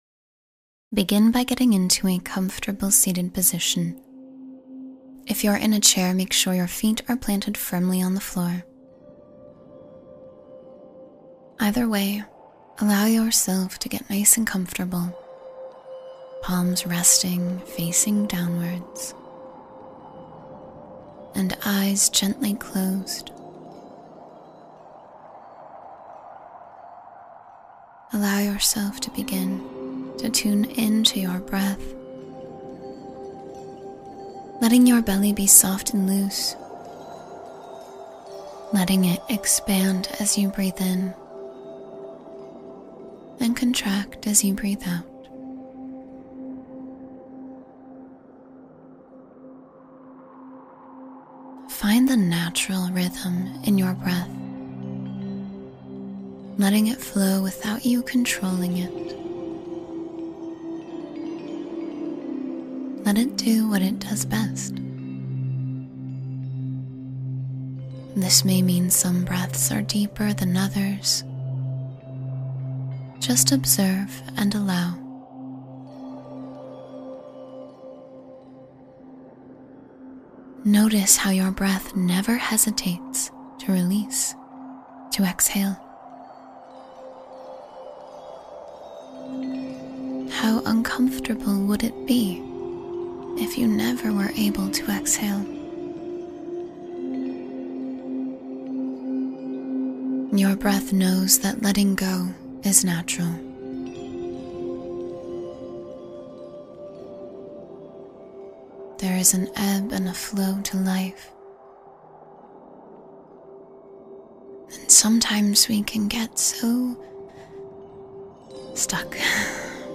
Sending Love and Light to Your Soul — Guided Meditation for Healing and Upliftment